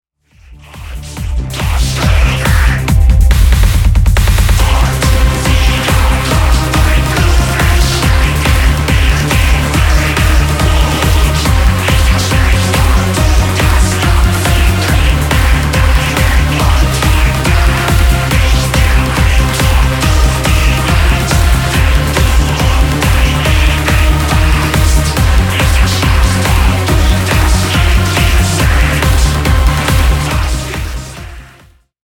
Darkwave, EBM, Industrial